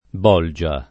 b0lJa] s. f.; pl. -ge — ormai generale la pn. con -o- aperto, contro una più ant. pn. chiusa conforme all’etimo (bulga con -u- breve, gallicismo del lat. tardo, ritornato attraverso il fr. mediev. bolge, mod. bouge) e attestata fino a metà dell’800 — questo, così nel sign. ant. di «borsa» (sopravvissuto nel dim. bolgetta), come nel sign. dantesco di «sezione dell’Inferno» (col comp. Malebolge) e nei frequenti usi fig. («luogo di confusione») del linguaggio corrente